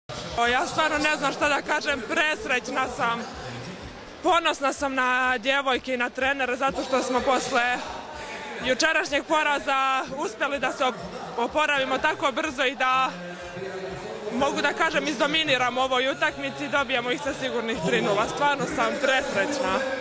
IZJAVA TIJANE BOŠKOVIĆ